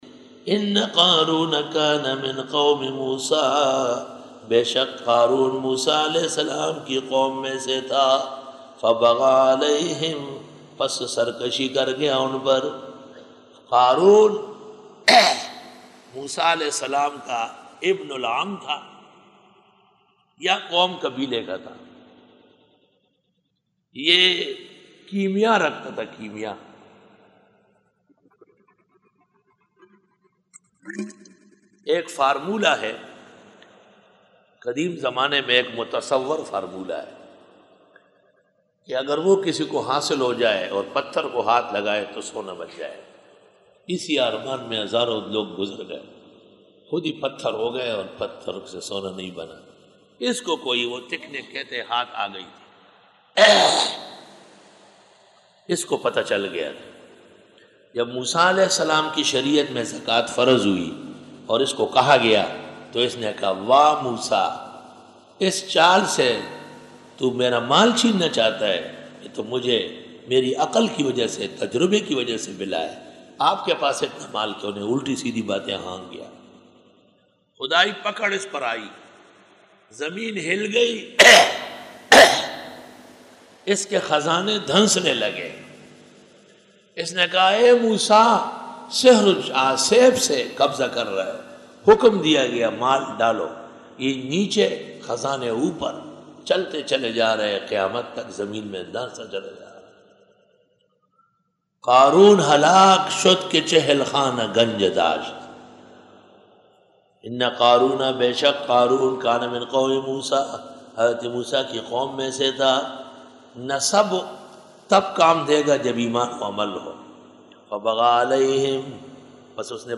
Dora-e-Tafseer 2005